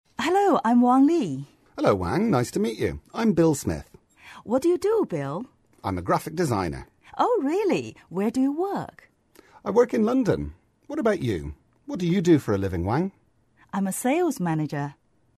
english_5_dialogue_1.mp3